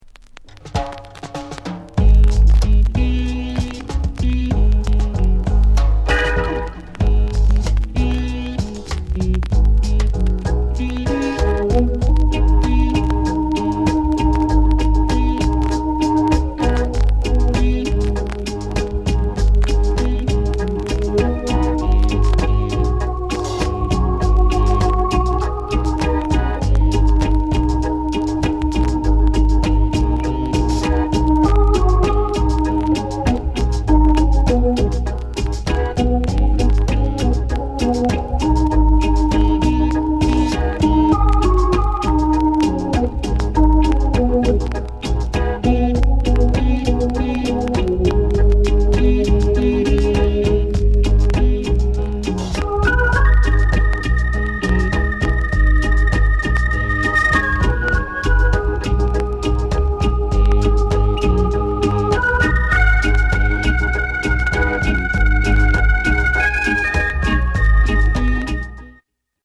JAZZY REGGAE